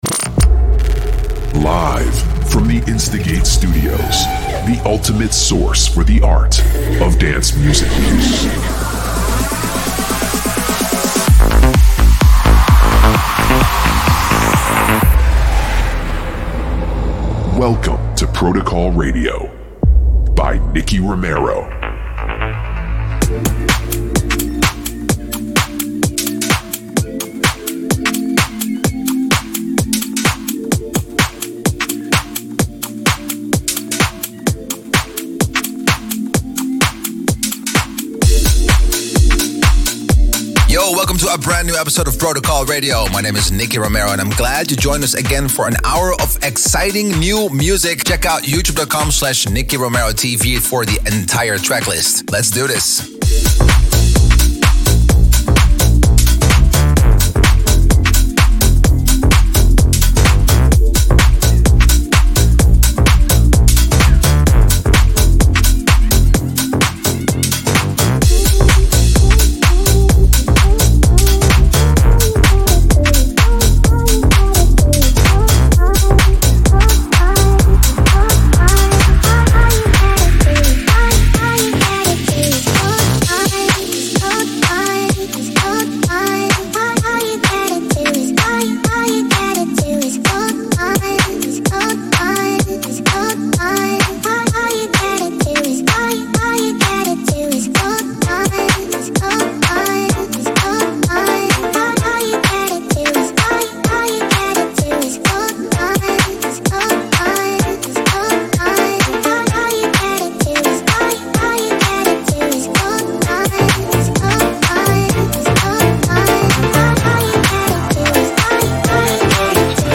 music DJ Mix in MP3 format
Genre: Electro House